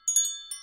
bells3.ogg